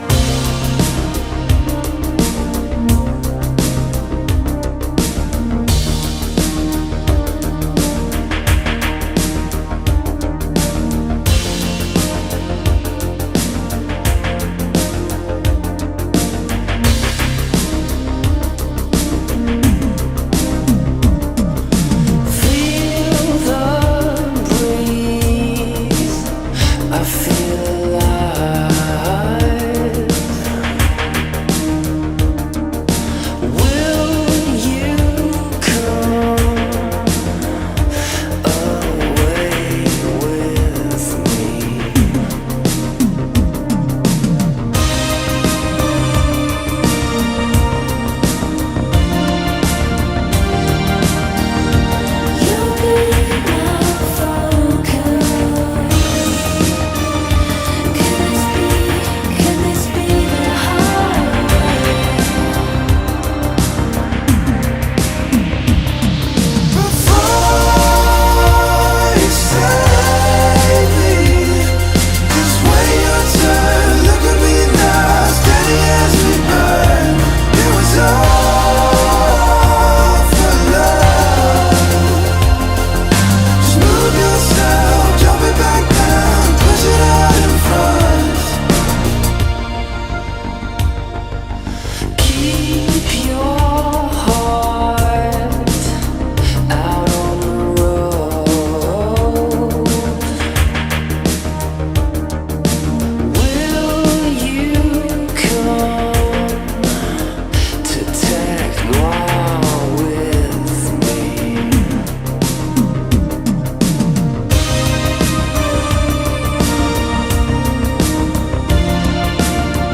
synthwave